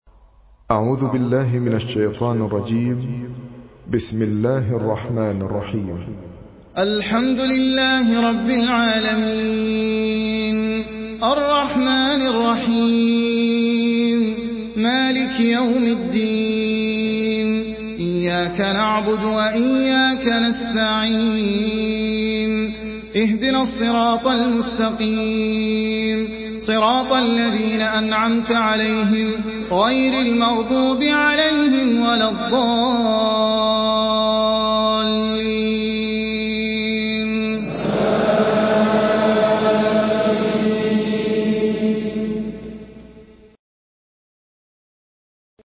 Surah Al-Fatihah MP3 by Ahmed Al Ajmi in Hafs An Asim narration.
Murattal Hafs An Asim